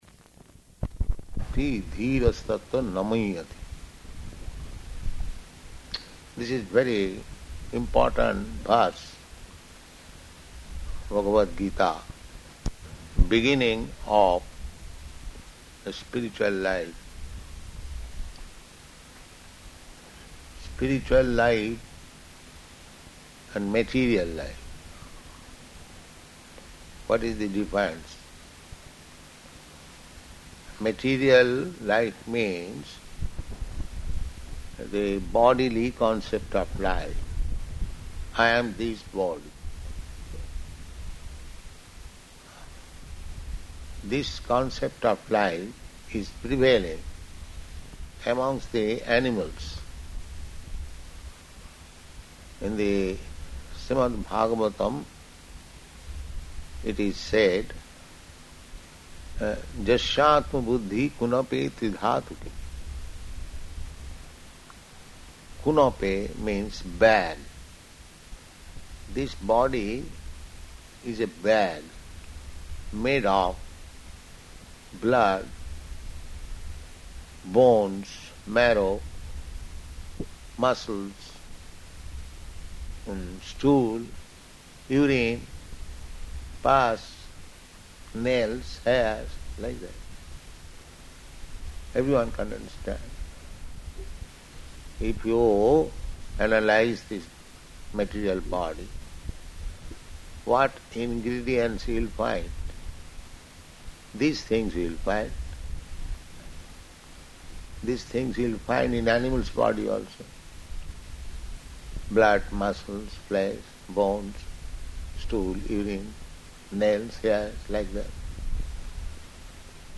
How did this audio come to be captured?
Location: Auckland